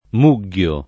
vai all'elenco alfabetico delle voci ingrandisci il carattere 100% rimpicciolisci il carattere stampa invia tramite posta elettronica codividi su Facebook mugghiare v.; mugghio [ m 2ggL o ], ‑ghi — pop. o poet. mugliare : muglio [ m 2 l’l’o ], mugli